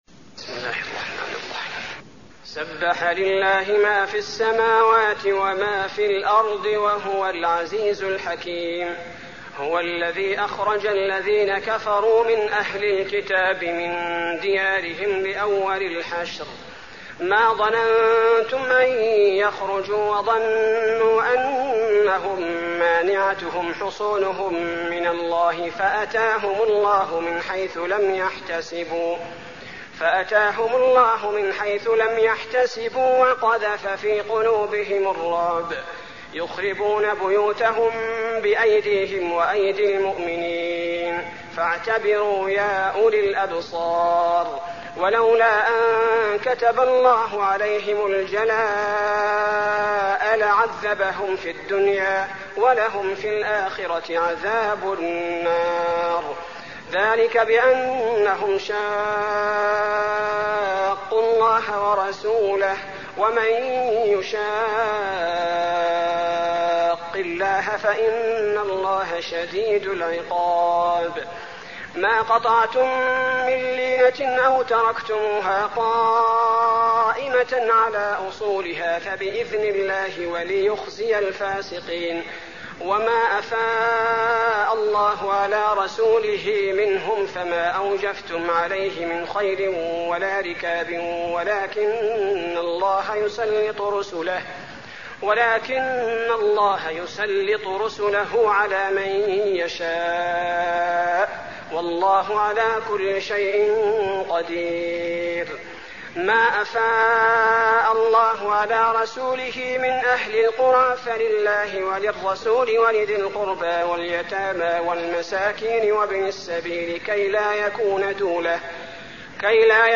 المكان: المسجد النبوي الحشر